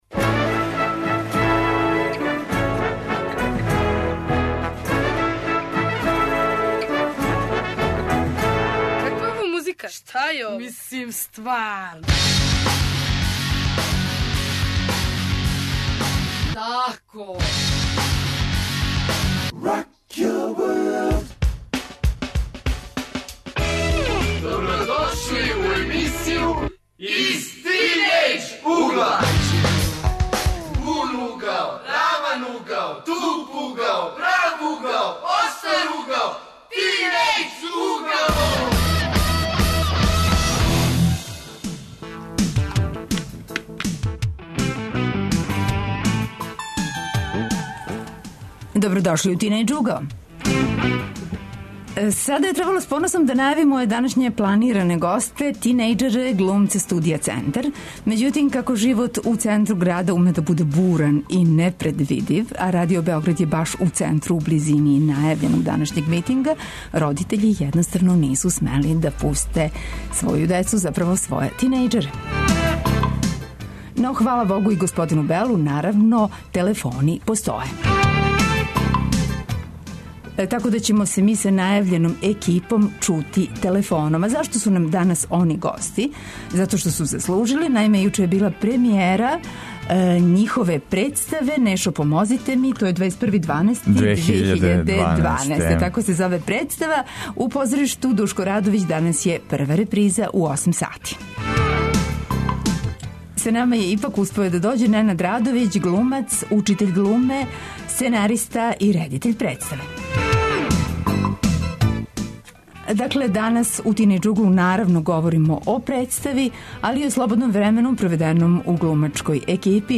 а чланове глумачке групе укључујемо путем телефона.